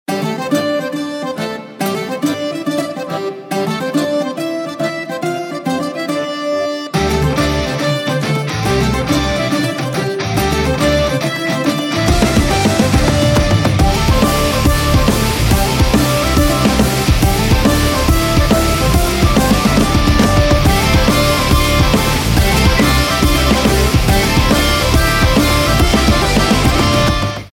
• Качество: 320, Stereo
без слов
инструментальные
Народные
бодрые
эпичные
шотландские